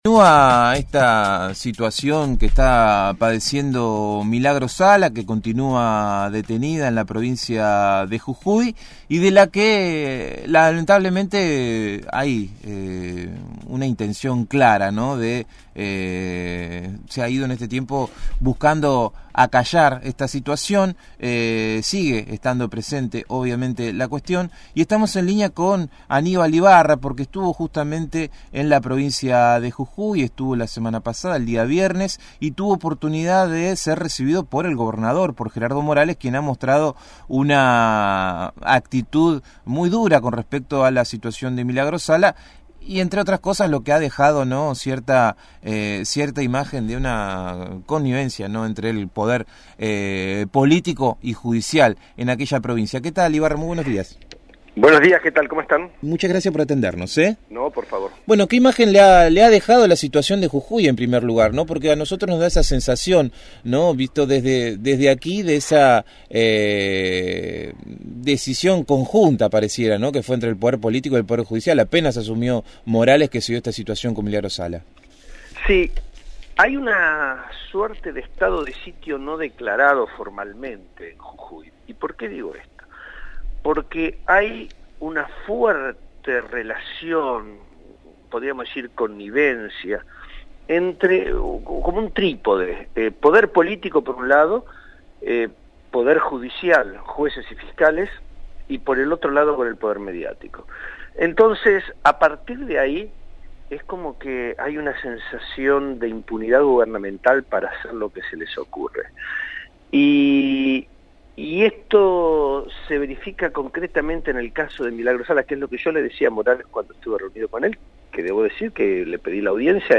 Aníbal Ibarra, ex Jefe de Gobierno porteño, dialogó con el equipo de «El Hormiguero» sobre su viaje a Jujuy como integrante del comité jurídico que asesora a la defensa de Milagro Sala y sobre los encuentros que mantuvo con la titular del movimiento Tupac Amaru en la prisión donde se encuentra recluida, y con el gobernador de Jujuy, Gerardo Morales.